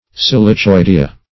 silicioidea - definition of silicioidea - synonyms, pronunciation, spelling from Free Dictionary Search Result for " silicioidea" : The Collaborative International Dictionary of English v.0.48: Silicioidea \Sil`i*ci*oi"de*a\, n. pl.